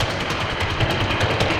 RI_DelayStack_150-01.wav